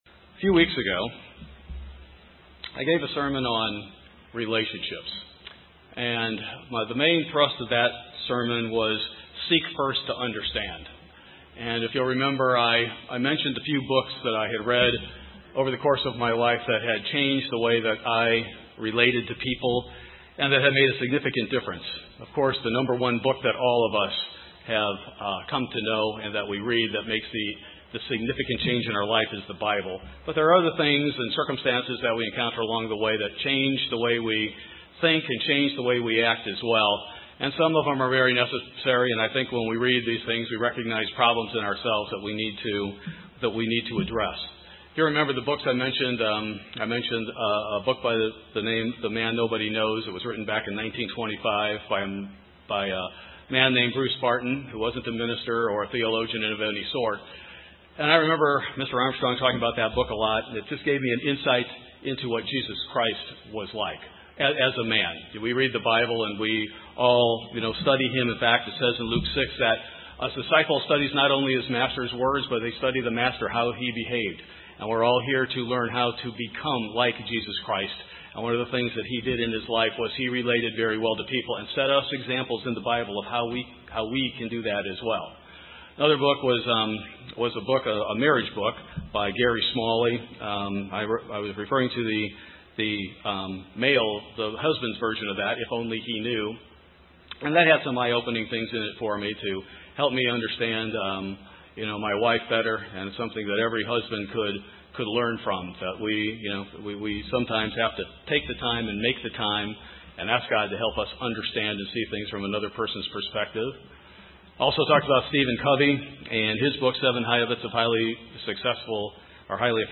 We can glean the components of healthy relationships—with God, our spouses, children, and brethren—from the Bible. In this sermon, we look at another key to developing good relationships, tying tips from well-known experts in the field to Biblical examples and admonitions.